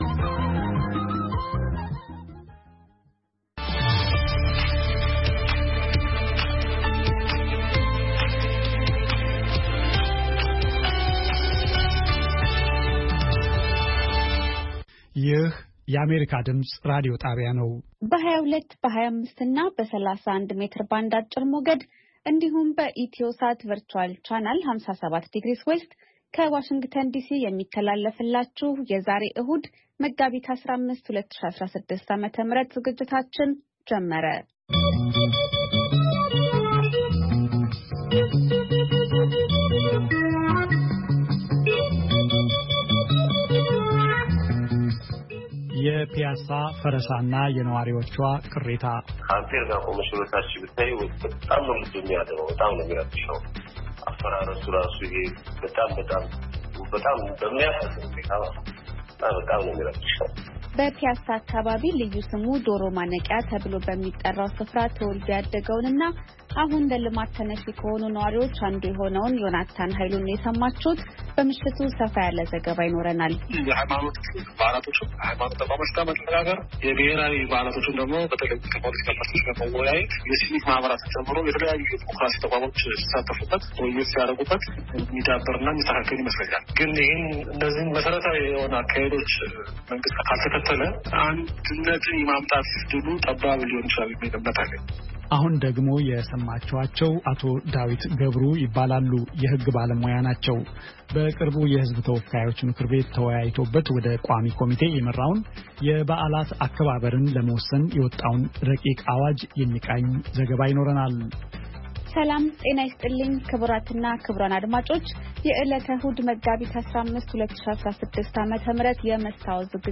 ዕሁድ፡- ከምሽቱ ሦስት ሰዓት የአማርኛ ዜና